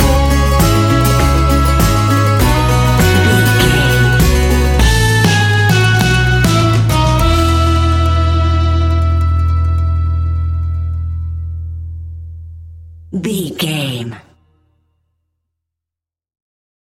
Uplifting
Ionian/Major
acoustic guitar
mandolin
ukulele
lapsteel
drums
double bass
accordion